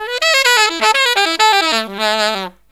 63SAXMD 12-L.wav